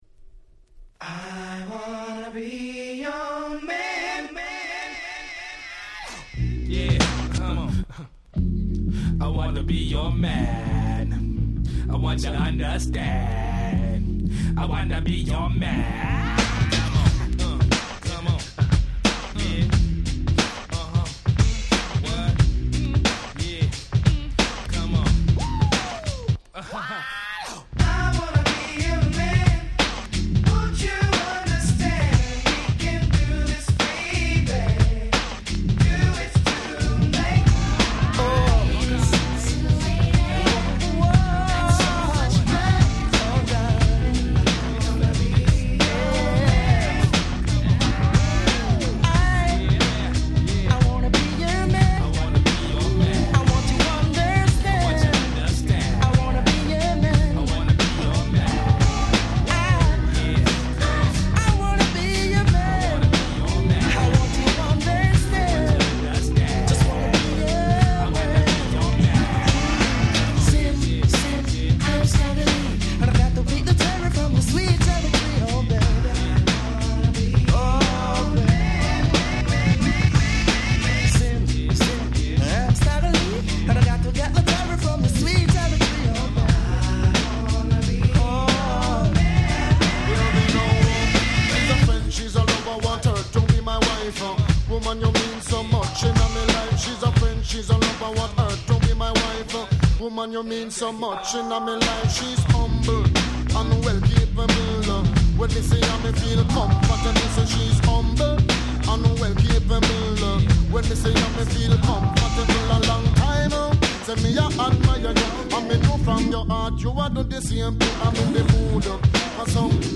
94' Nice Reggae !!